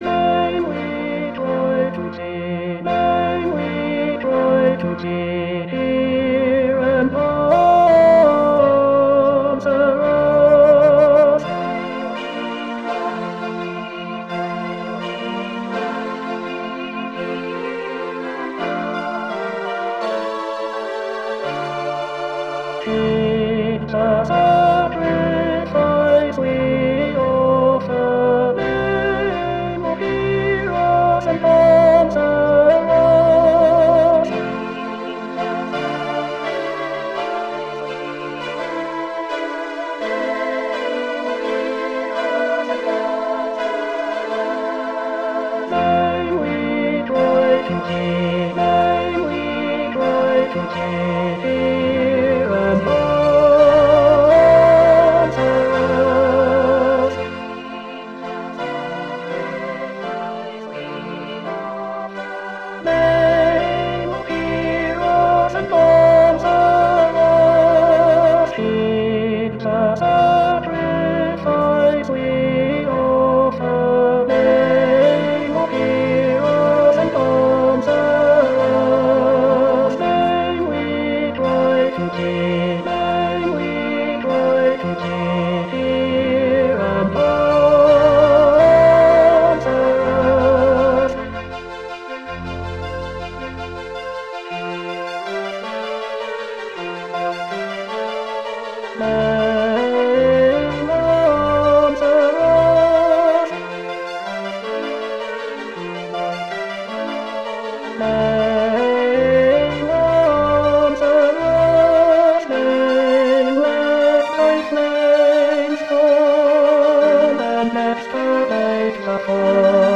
Tenor Tenor 1